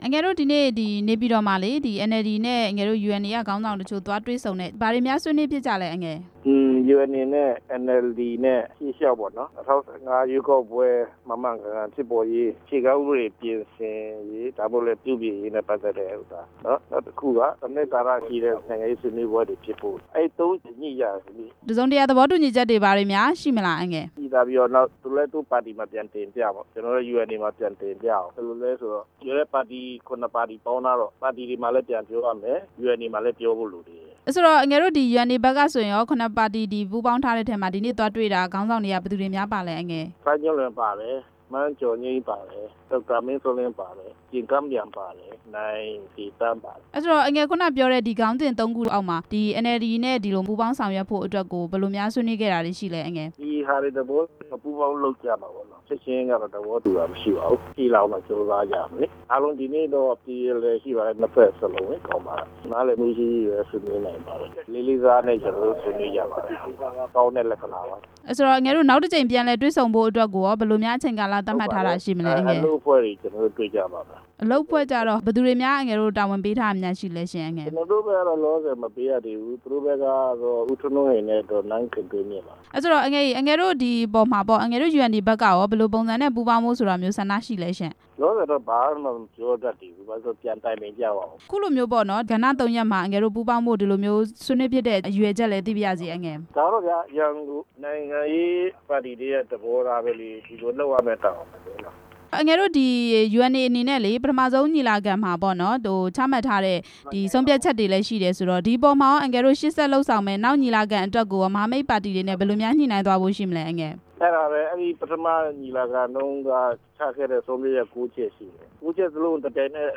NLD နဲ့ UNA ခေါင်းဆောင်တွေ ဆွေးနွေးတဲ့အကြောင်း မေးမြန်းချက်